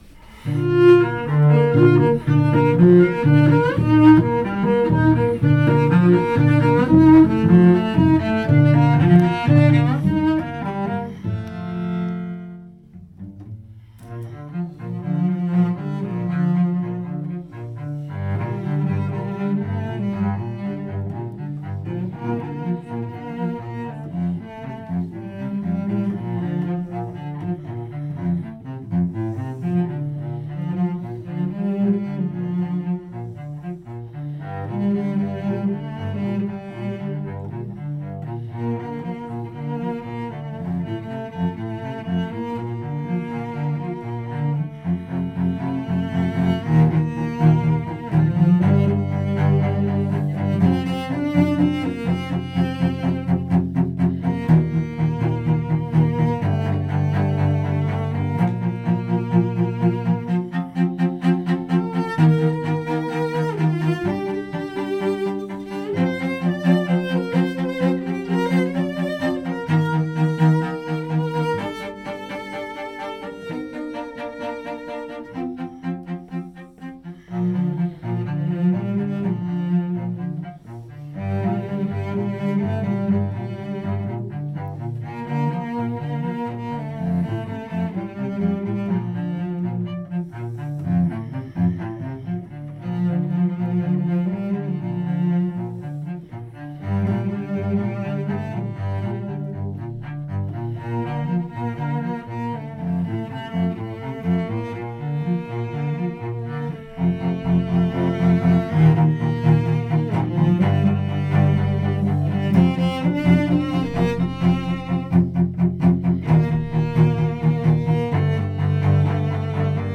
Prepísaná na violončelo